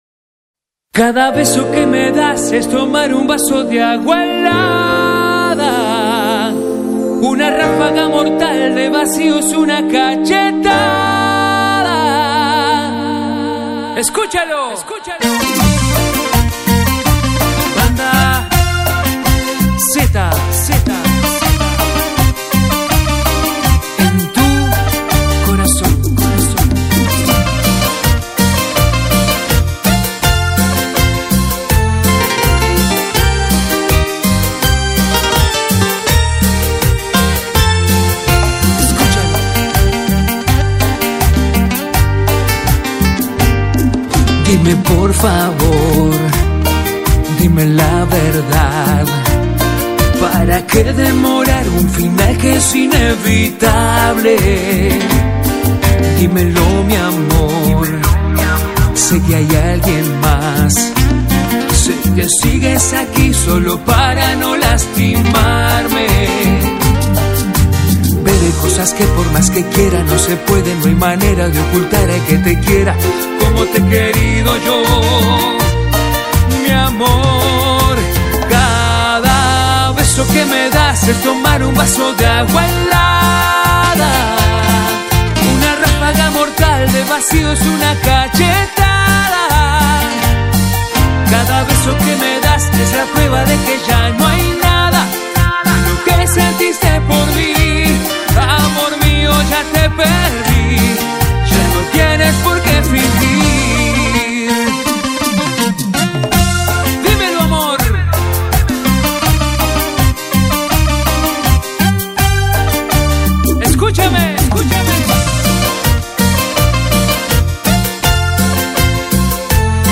Carpeta: Cumbia y + mp3